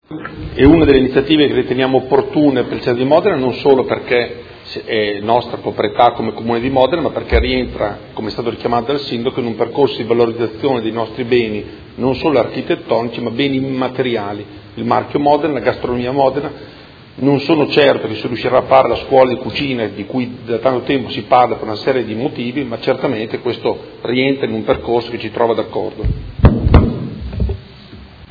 Seduta del 19/04/2018. Dichiarazione di voto su proposta di deliberazione: Approvazione della Convenzione tra i Comuni di Modena, Castelfranco Emilia, Nonantola e San Cesario sul Panaro per la salvaguardia e la valorizzazione del complesso di Villa Sorra